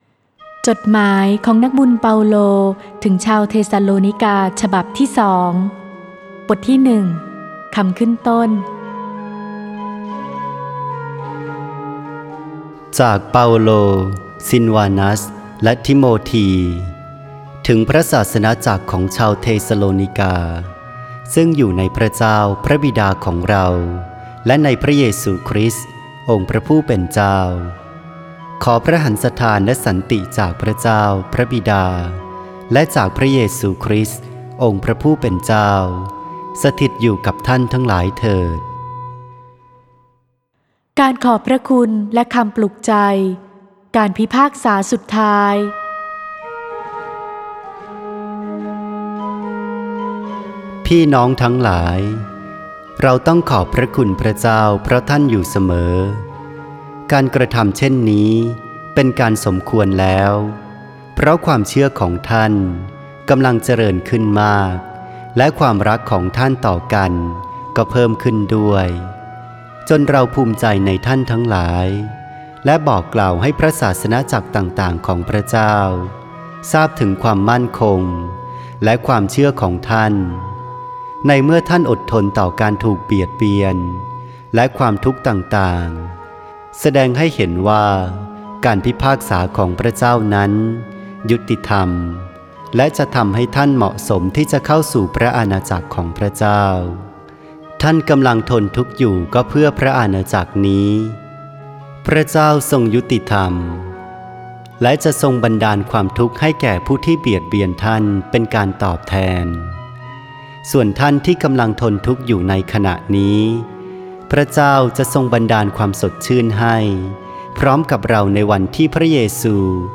(ไฟล์ "เสียงวรสาร" โดย วัดแม่พระกุหลาบทิพย์ กรุงเทพฯ)